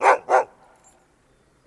Гав-гав